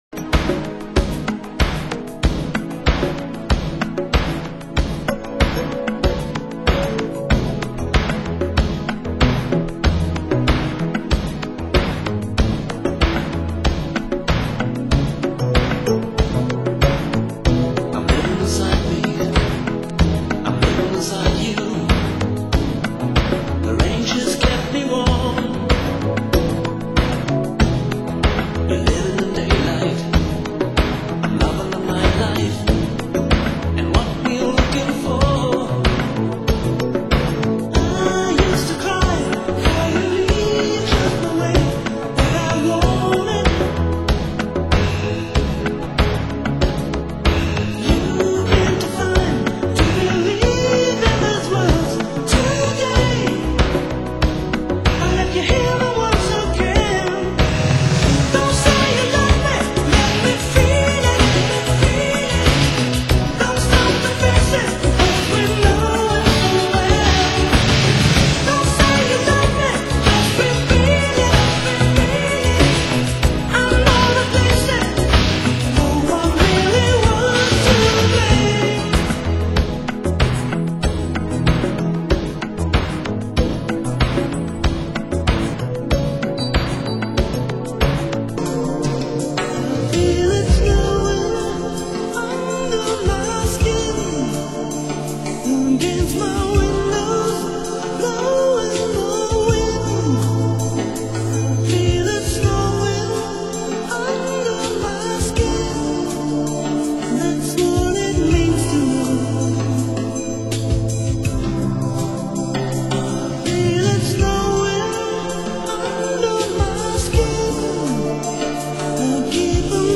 Genre: Synth Pop